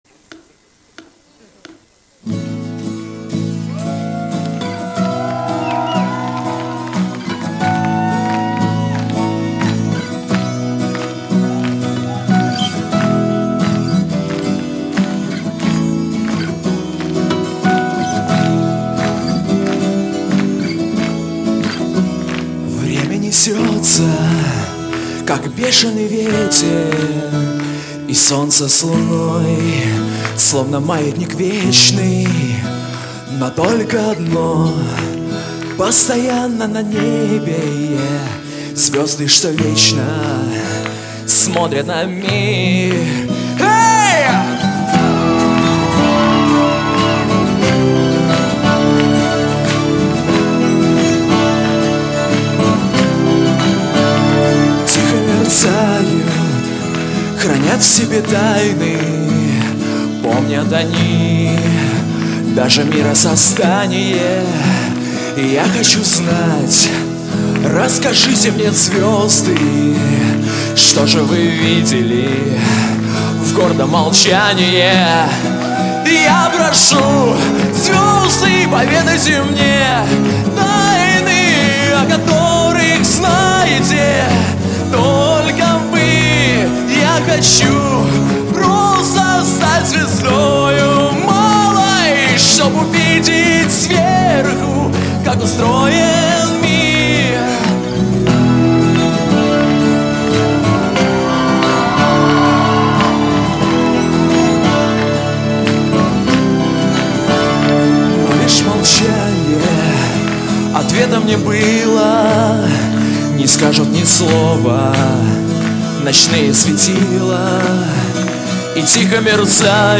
Запись была сделана мной с Тюнера AverMedia TV-Phon